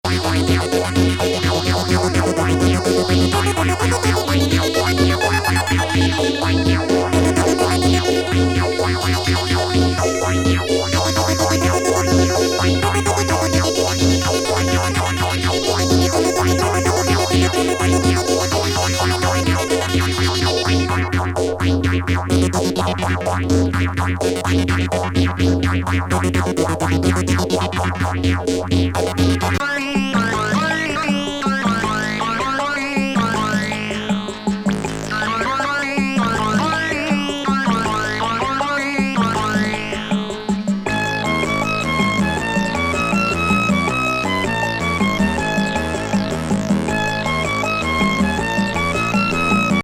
素人さん応募のシンセサイザー作品集！